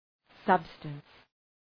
Shkrimi fonetik {‘sʌbstəns}